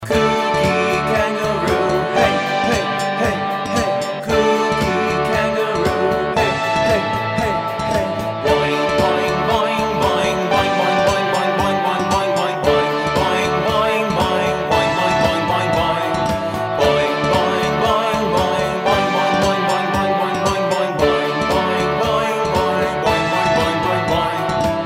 This is an action song for learning about Australia.
Vocal mp3 Song Track